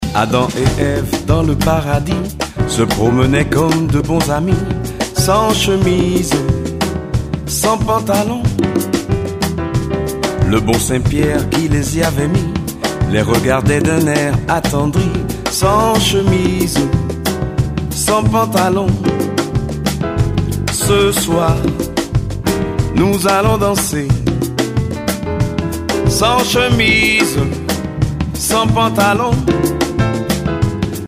Contrebasse